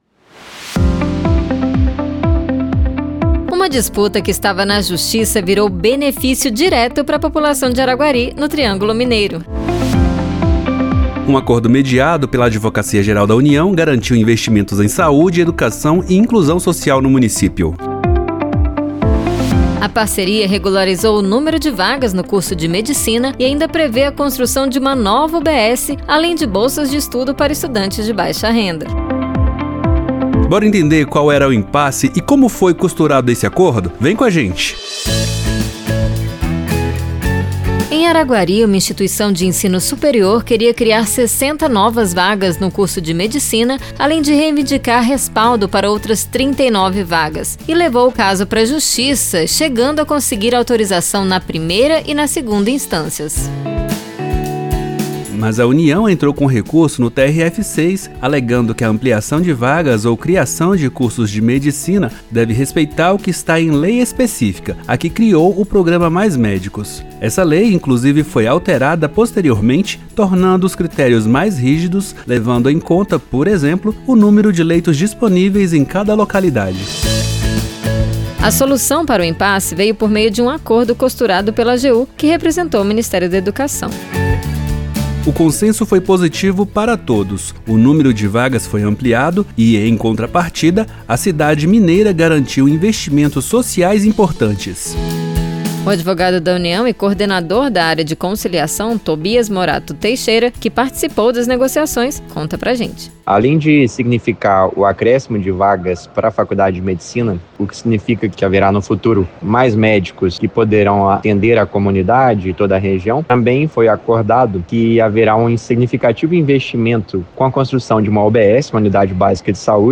No AGU Brasil desta sexta-feira (16): No programa de hoje você vai conferir uma reportagem especial que mostra como um caso complexo pode ter uma solução que beneficia a todos os envolvidos, da empresa privada à população local, passando por economia dos cofres públicos, ao encerrar mais um processo na justiça.